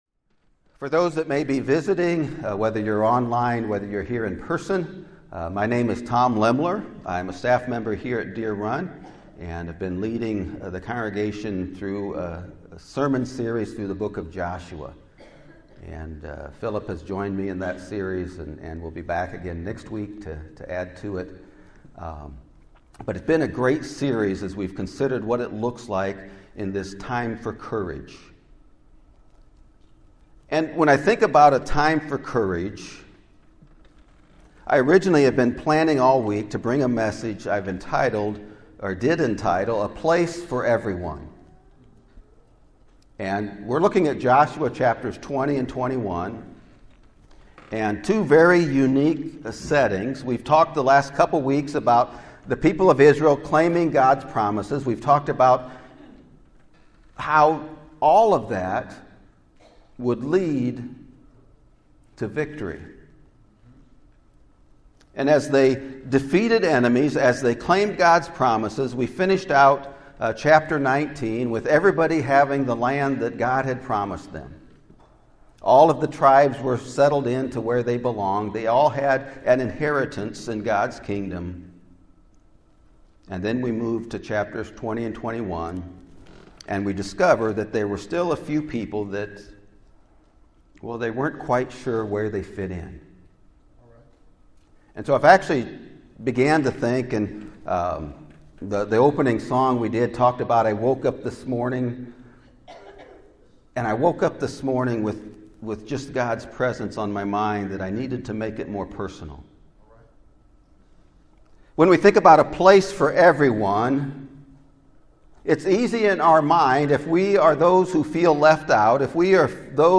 A Place For ME! (Sermon Audio)